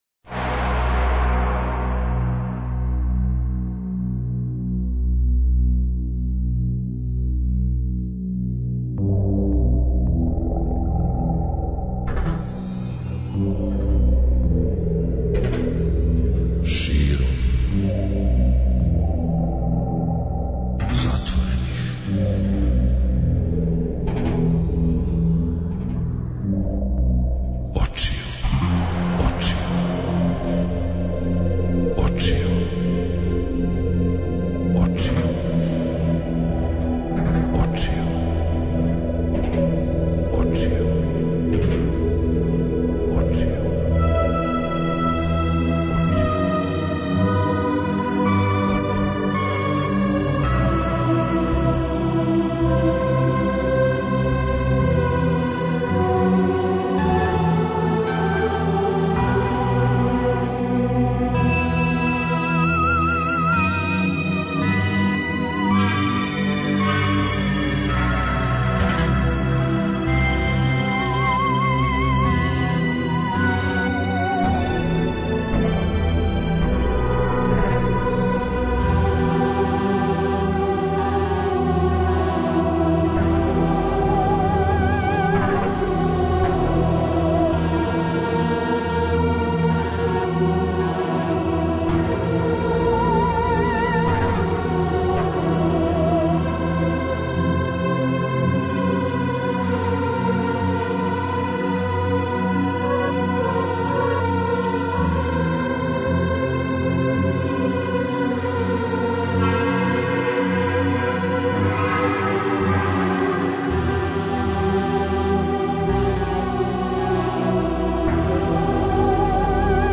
Ове летње ноћи уживаћемо у најлепшим баладама, како домаћим, тако и страним.